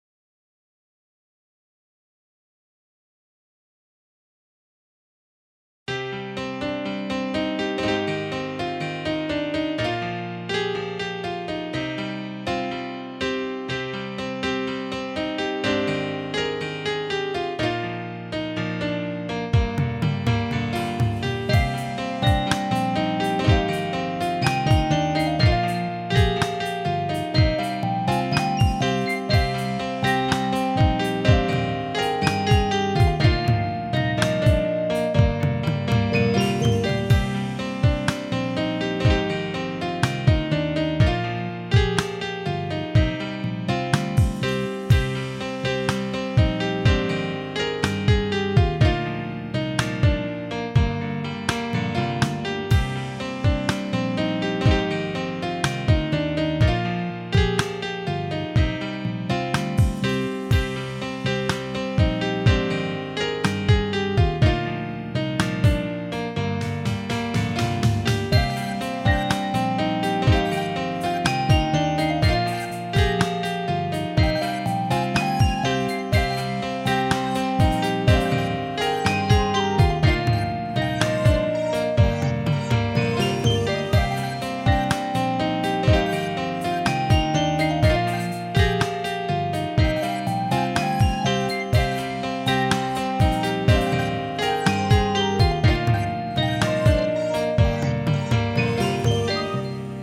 아무튼 이번 곡은 역시 피아노가 주가 되는 곡이고...
여전히 알앤비네요 ㅋㅋㅋ
드럼은 좀 어쿠스틱하게 해봤습니다
리미터 등등 마스터단에 걸기 전 상태이고,
아날로그 신스 (생각이 안나네요) 두개 딱 썼습니다.
피아노 음색이 좀아쉽네요...편곡도 리듬파트랑 조율이필요한거같아요 잘들엇어용 ㅎㅎ